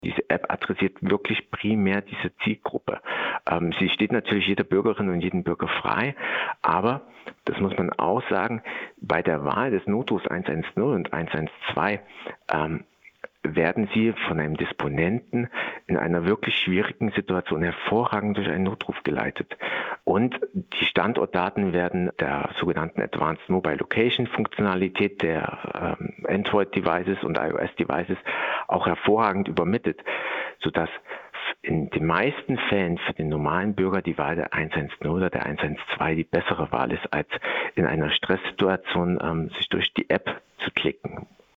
Interview - Nora App - PRIMATON